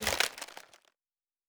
pgs/Assets/Audio/Fantasy Interface Sounds/Wood 04.wav at master
Wood 04.wav